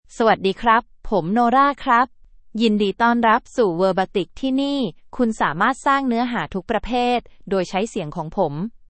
FemaleThai (Thailand)
Nora — Female Thai AI voice
Nora is a female AI voice for Thai (Thailand).
Voice sample
Listen to Nora's female Thai voice.